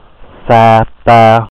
Near to the now Sa Pa townlet is "Sa Pả commune", which shows the origin in Hmong language of the location name.[a] Sa Pa is pronounced with "S" almost as soft as the "Ch" sound of French, "Sh" in English, or "S" in standard Vietnamese, so Chapa as the French called it.
Sa_Pa.ogg.mp3